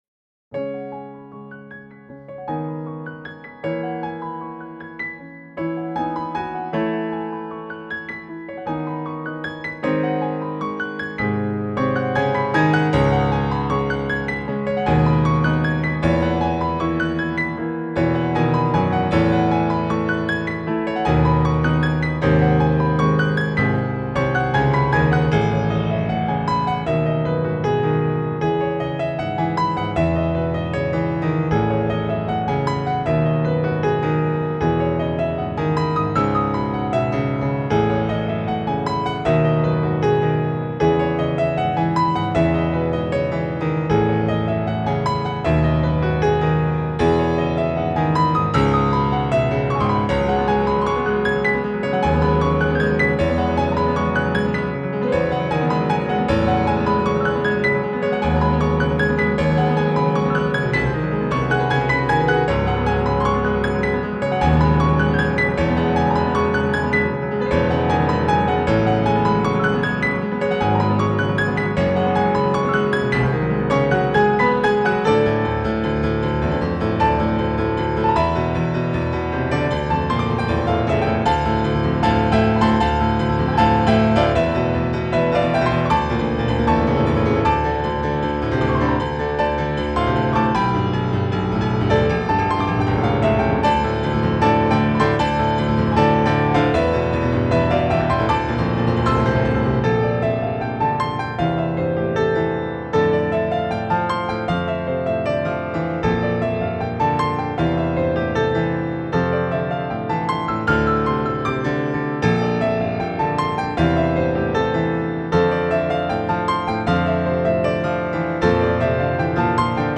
PIANO
PIANO ARRANGEMENT